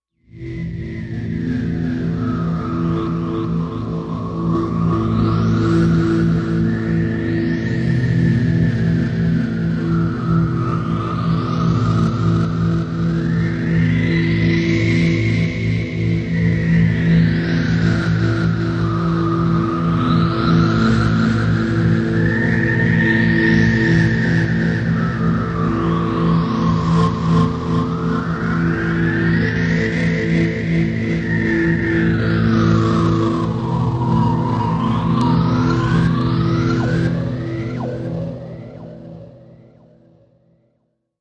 Dark Wind Mystic Deep Cave Wind Swirling